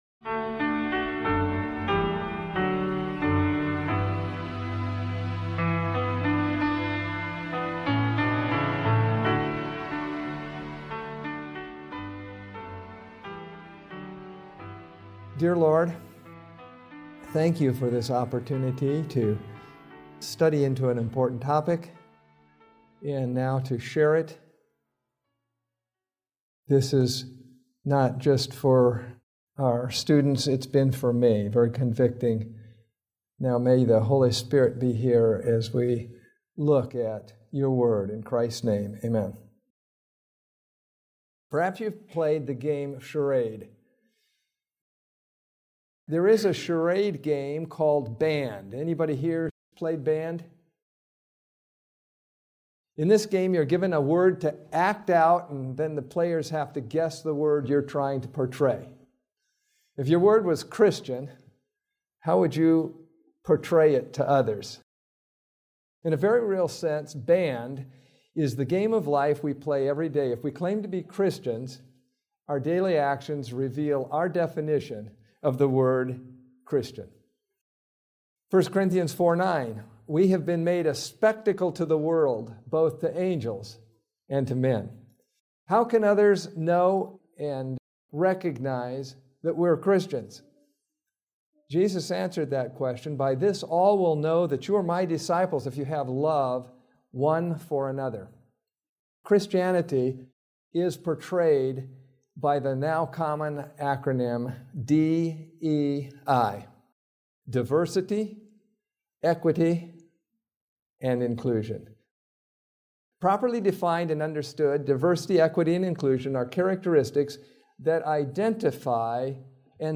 This sermon explores diversity, equity, and inclusion through a biblical lens—revealing them not as modern trends, but as reflections of God’s design for harmony, justice, and holy community. Centered on Christ’s transforming love, it challenges believers to pursue unity of purpose, disciplined obedience, and Spirit-led inclusion that protects truth while cultivating genuine unity.